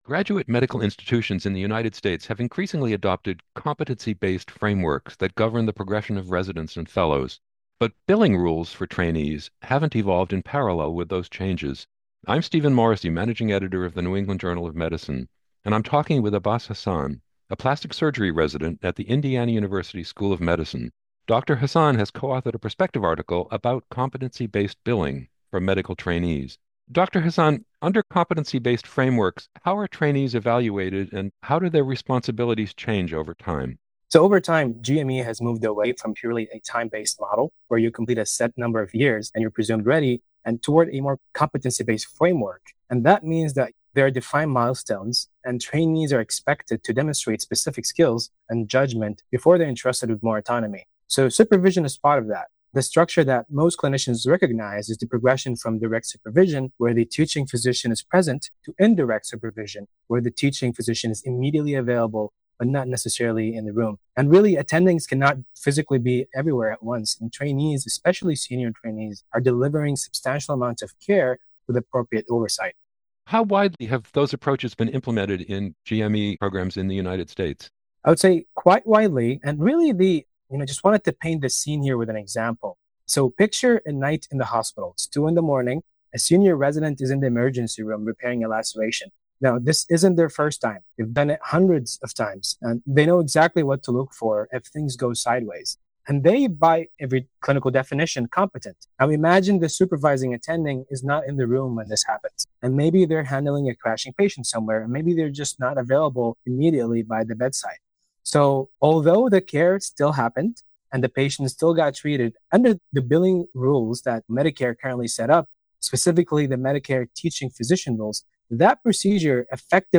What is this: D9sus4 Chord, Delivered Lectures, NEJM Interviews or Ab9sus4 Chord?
NEJM Interviews